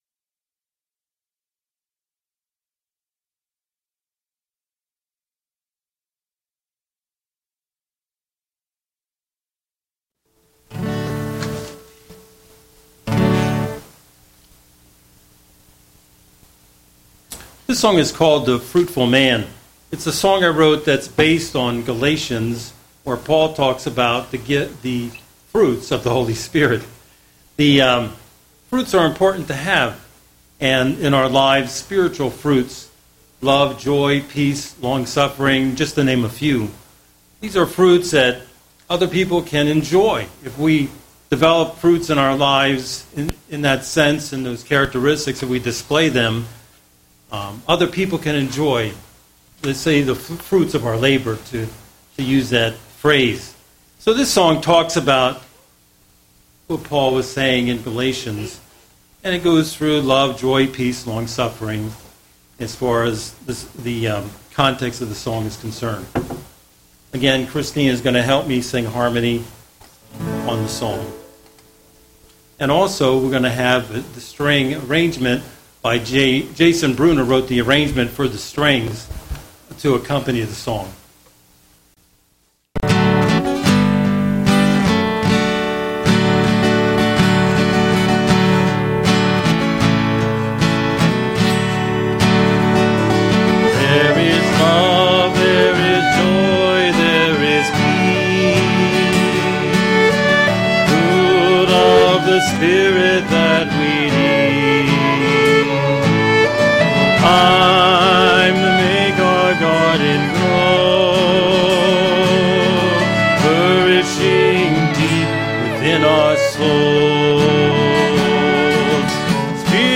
Vocal Video
Vocals.
string trio
This arrangement includes vocal harmonies by